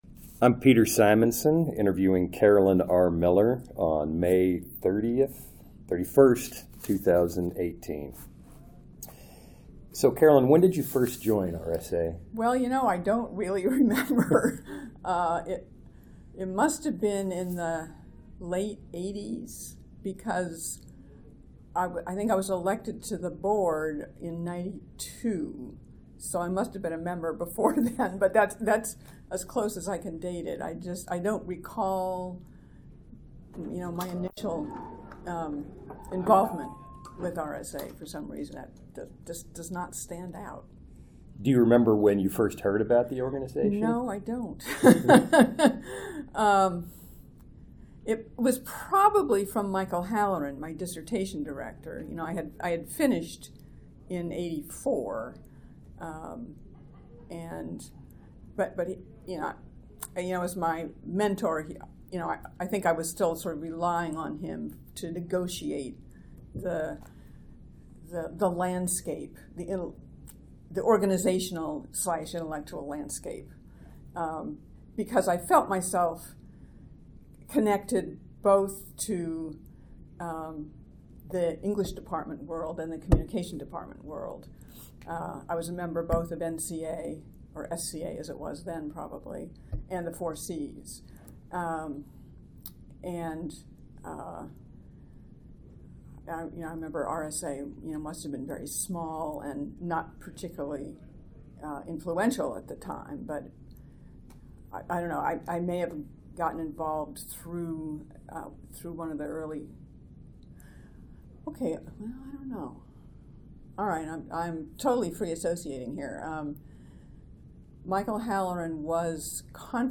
Oral History
2018 RSA Conference in Minneapolis, Minnesota